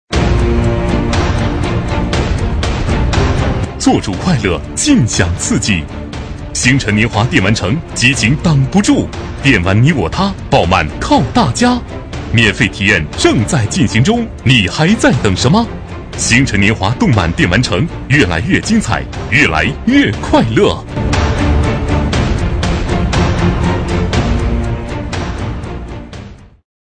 B类男01
【男1号广告】星辰年华电玩城